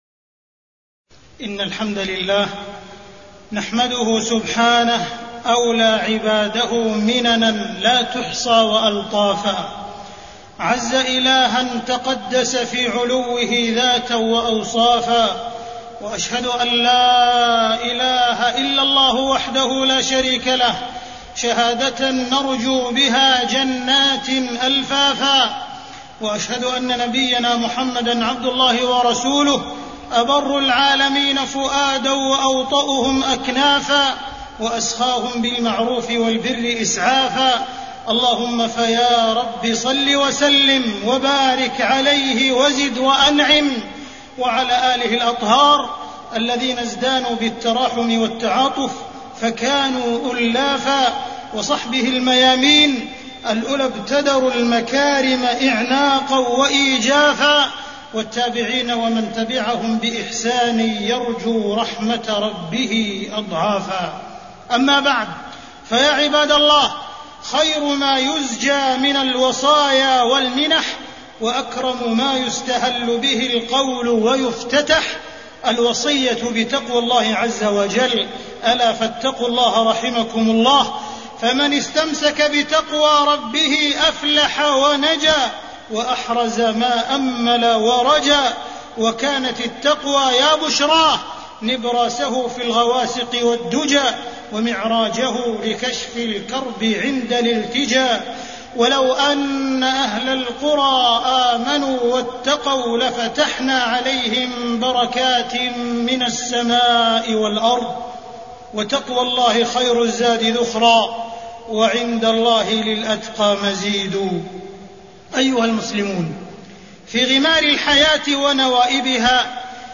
تاريخ النشر ٢٢ صفر ١٤٢٩ هـ المكان: المسجد الحرام الشيخ: معالي الشيخ أ.د. عبدالرحمن بن عبدالعزيز السديس معالي الشيخ أ.د. عبدالرحمن بن عبدالعزيز السديس خير الزاد التقوى